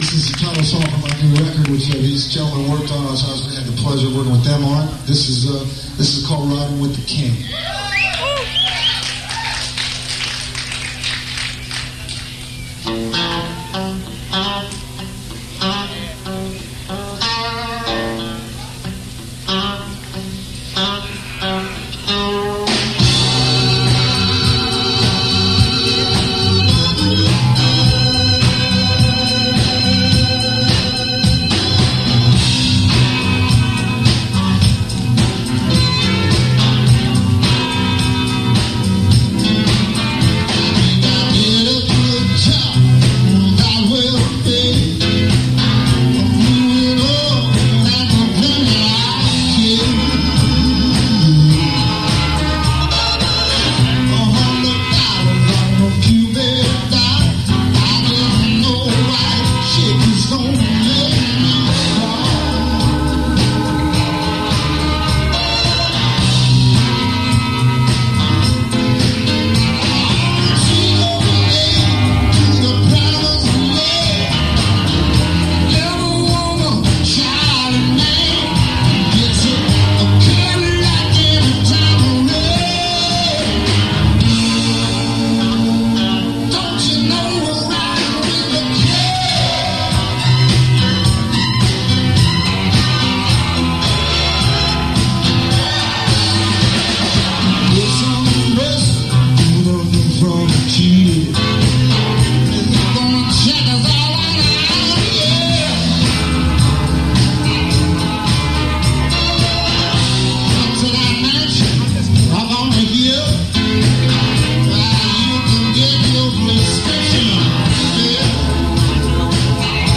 Attn: muddy and tinny sound